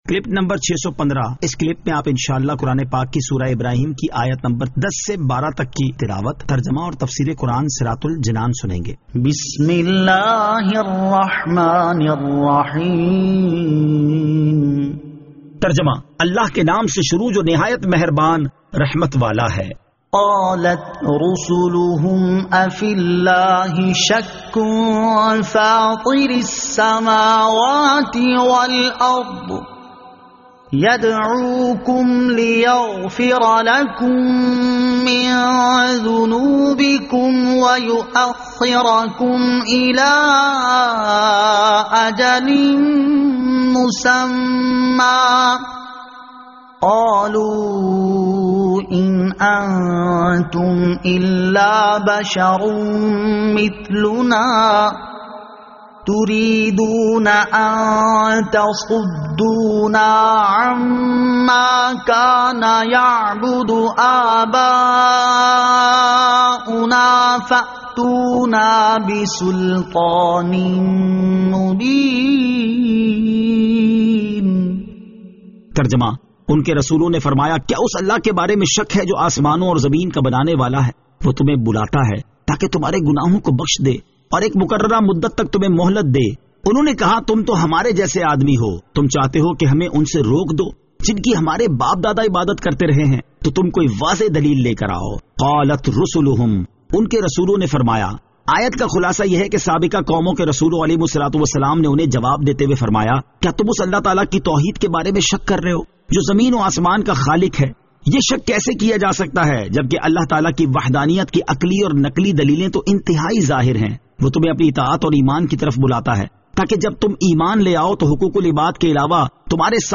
Surah Ibrahim Ayat 10 To 12 Tilawat , Tarjama , Tafseer